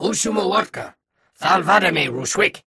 Unggoy speech from Halo: Reach.
Grunt_look_lngtme8.ogg.mp3